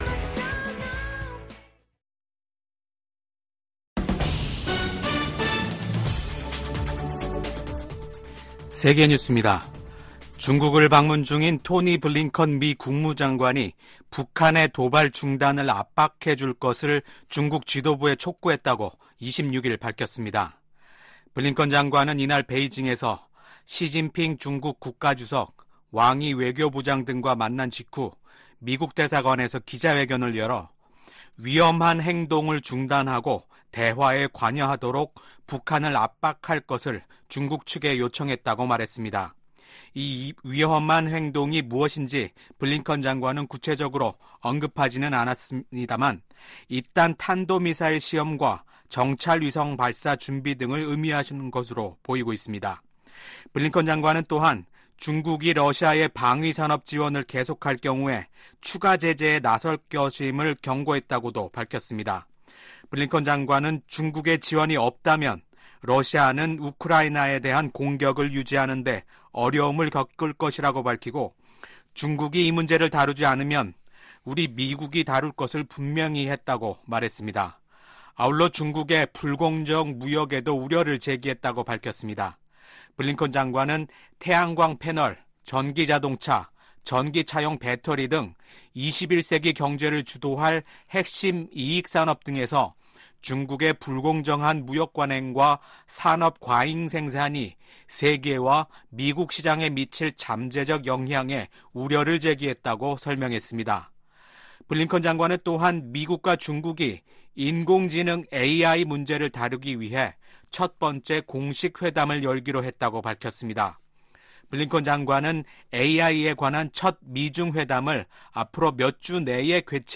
VOA 한국어 아침 뉴스 프로그램 '워싱턴 뉴스 광장' 2024년 4월 27일 방송입니다. 미국과 한국, 일본이 제14차 안보회의를 열고 지속적인 3국간 안보협력 의지를 재확인했습니다. 유엔 주재 미국 부대사는 중국과 러시아의 반대로 북한의 핵 프로그램에 대한 조사가 제대로 이뤄지지 못했다고 지적했습니다. 북한이 김정은 국무위원장이 참관한 가운데 신형 240mm 방사포탄 검수사격을 실시했습니다.